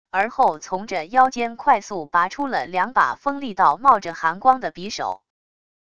而后从着腰间快速拔出了两把锋利到冒着寒光的匕首wav音频生成系统WAV Audio Player